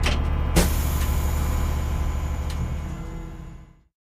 Armored Vehicles
mi_lav_idle_hatch_01_hpx
Light armored vehicle hatch shuts down with idle.